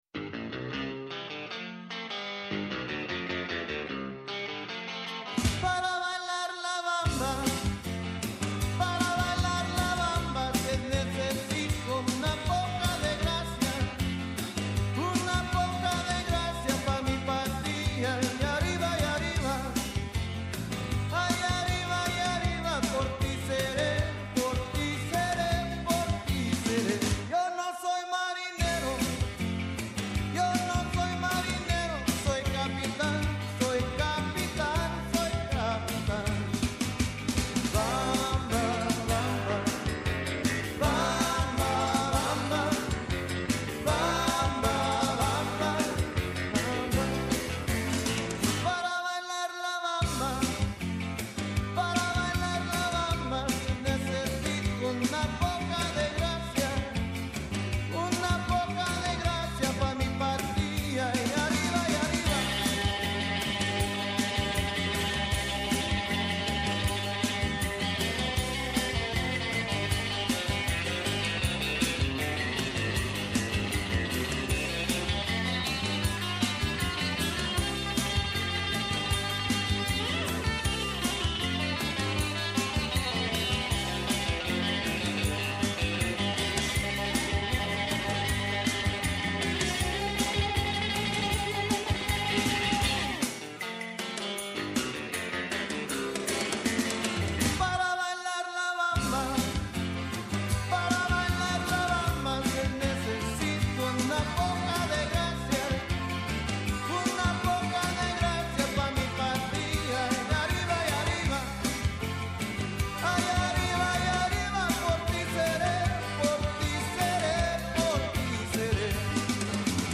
Μαζί μας στο στούντιο